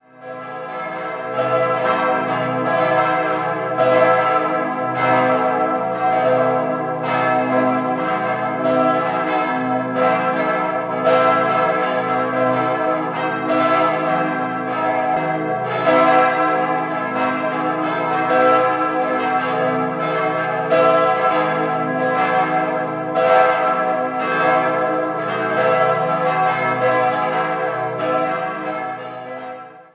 In den Jahren 1910 bis 1912 erfolgte der Bau der Kirche. 4-stimmiges Salve-Regina-Geläute: c'-e'-g'-a' Die große Glocke wurde 1958 von Rudolf Perner (Passau) gegossen, die drei anderen von Johann Hahn (Landshut) im Jahr 1911.